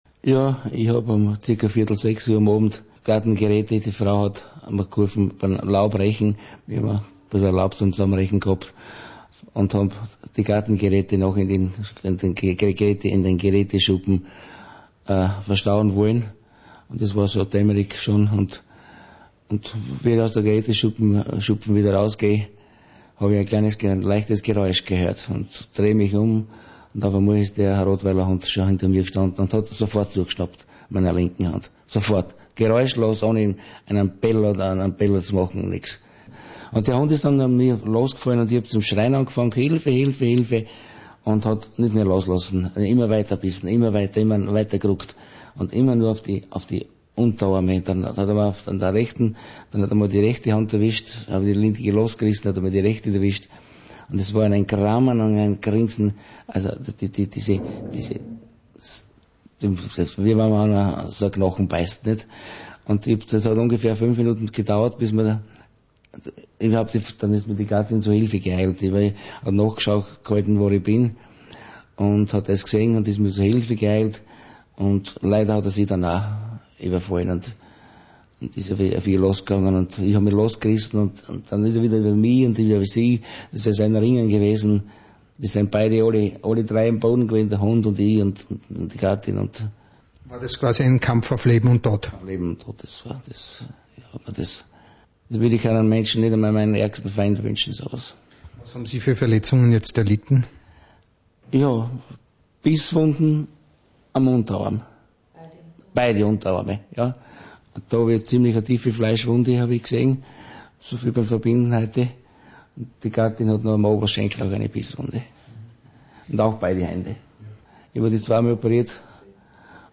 Nun mal das Radio-Interview: